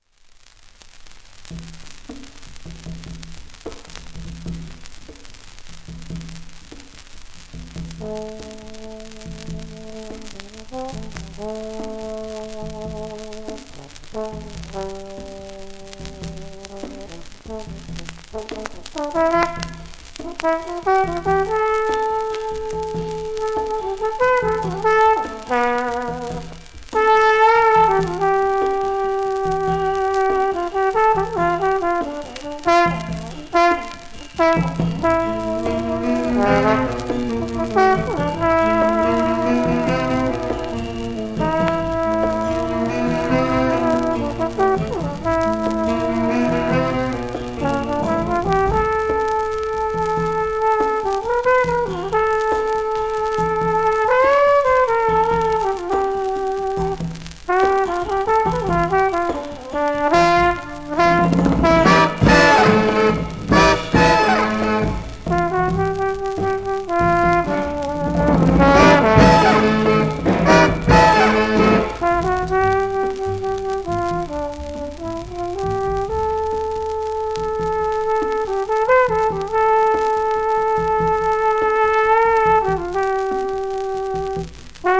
1954年録音
ビバップ、モダン時代のテナーサックス奏者で英国ジャズ界の重鎮